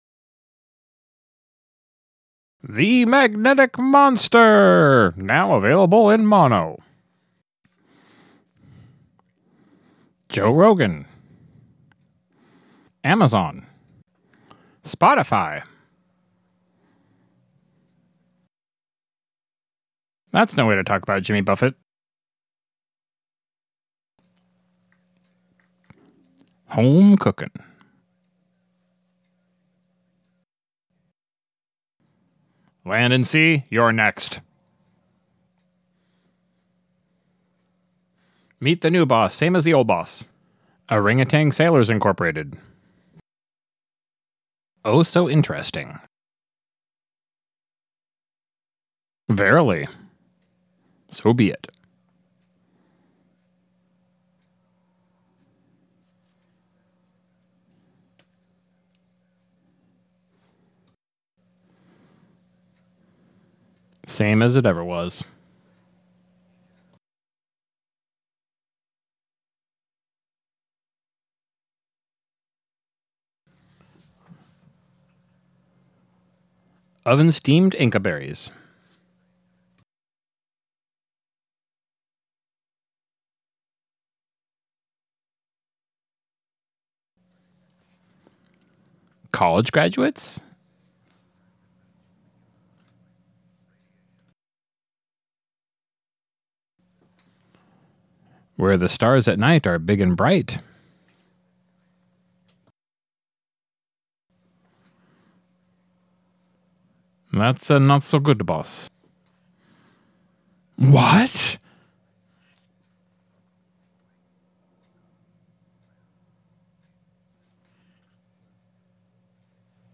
The Magnetic Monster MVM Version, Part 1 The Magnetic Monster MVM Version, Part 2 The Magnetic Monster MVM Version, Part 3 The Magnetic Monster MVM Version, Part 4 Here’s A “Riffs Only” Version of the audio, that you can sync up with your home copy of the film, if you’d like.
the-magnetic-monster-mid-valley-mutations-commentary.mp3